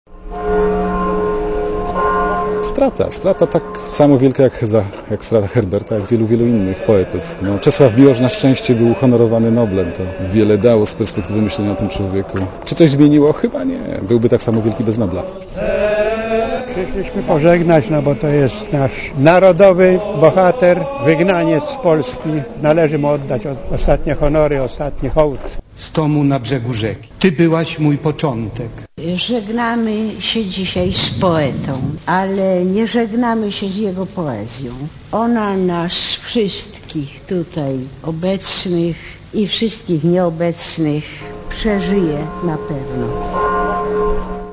Przed południem w Bazylice Mariackiej odbyło się nabożeństwo żałobne.
miloszpogrzeb.mp3